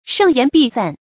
盛筵必散 shèng yán bì sàn
盛筵必散发音